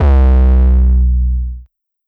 WHAT_S GOOD 808.wav